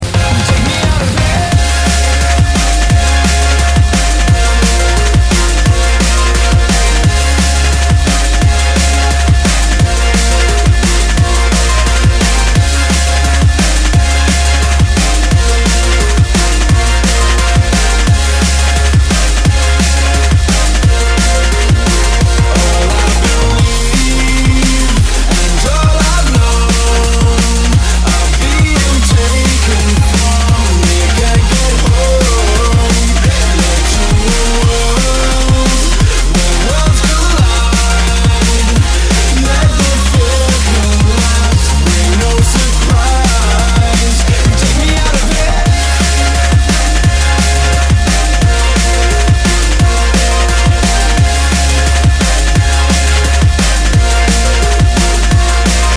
D'n'B